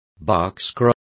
Complete with pronunciation of the translation of boxcar.